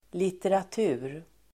Uttal: [literat'u:r]